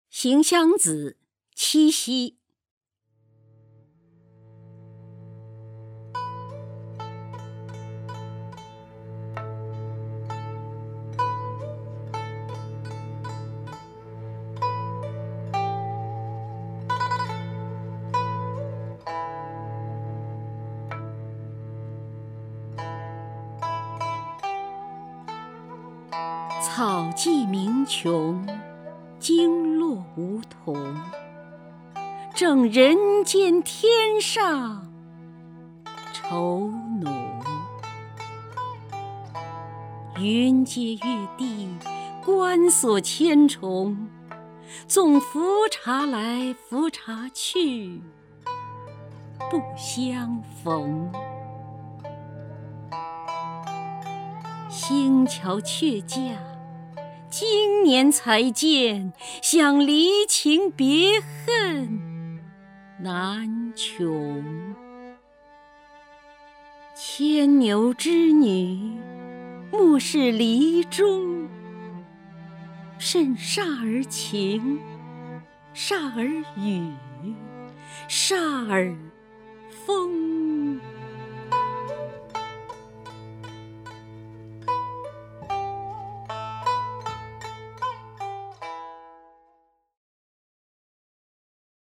首页 视听 名家朗诵欣赏 姚锡娟
姚锡娟朗诵：《行香子·草际鸣蛩》(（南宋）李清照)